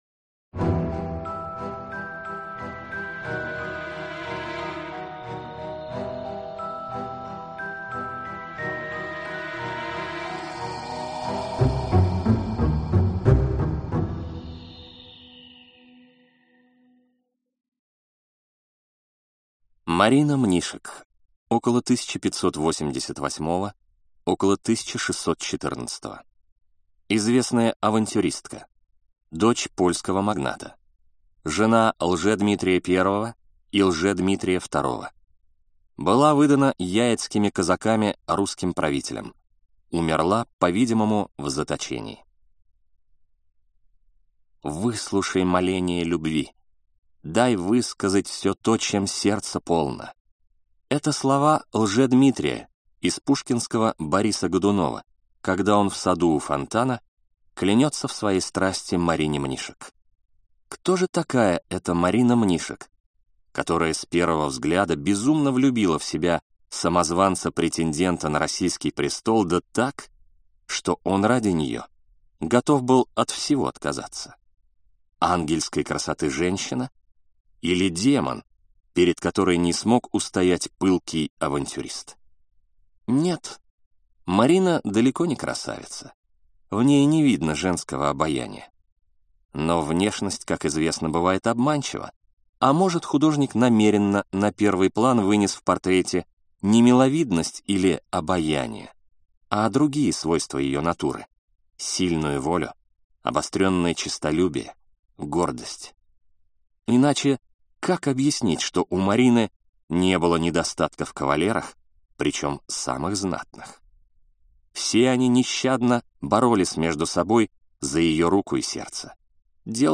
Aудиокнига Любовницы